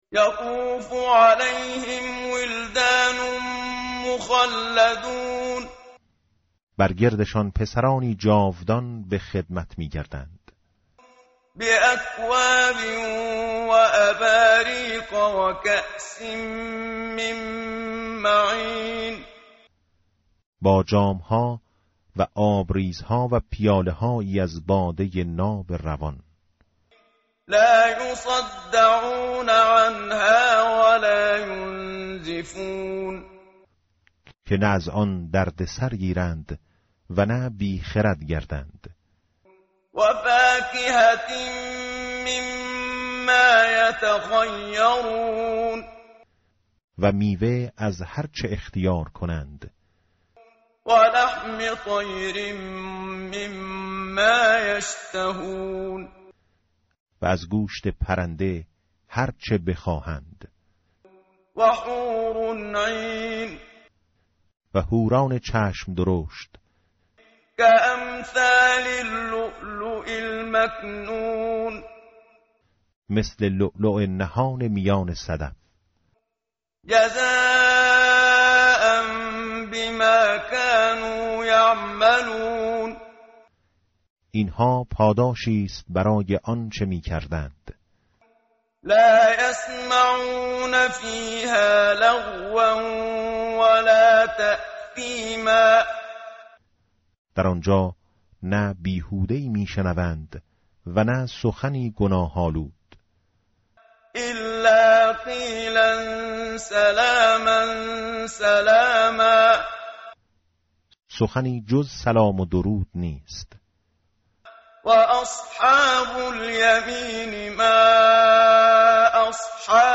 متن قرآن همراه باتلاوت قرآن و ترجمه
tartil_menshavi va tarjome_Page_535.mp3